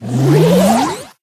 springboard_charge_01.ogg